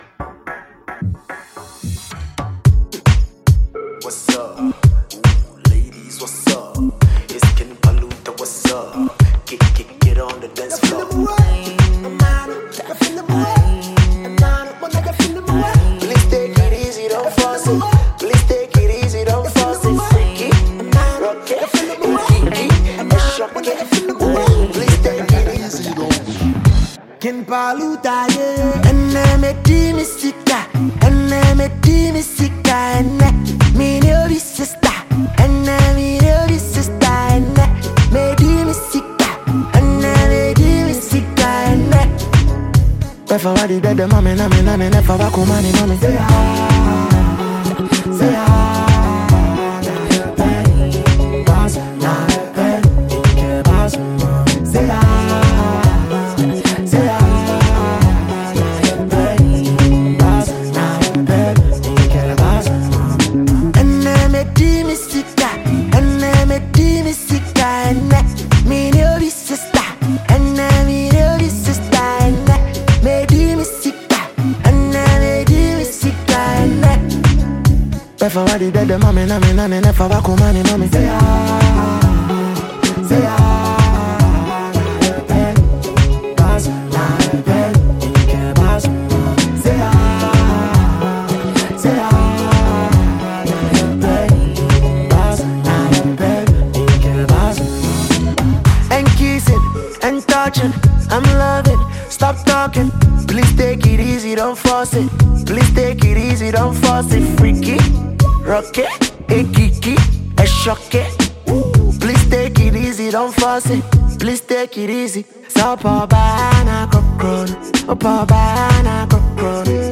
a highly-rated Ghanaian singer and rapper